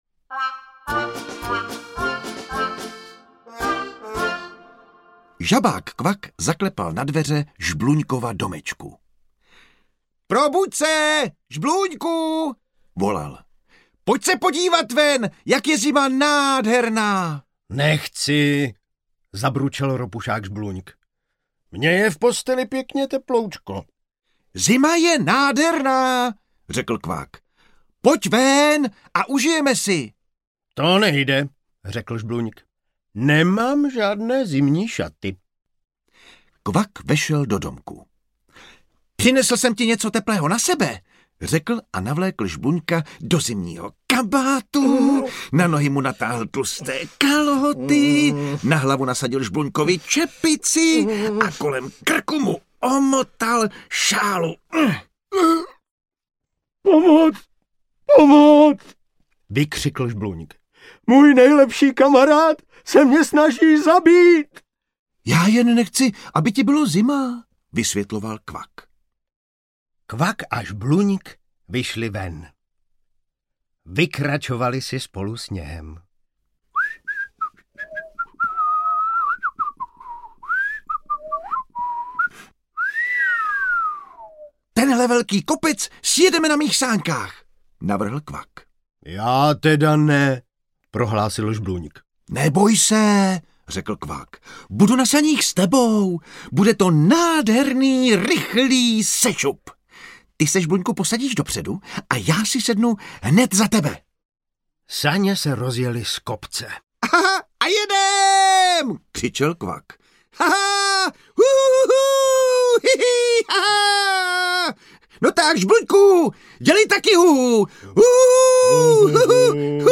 Kvak a Žbluňk od jara do Vánoc audiokniha
Ukázka z knihy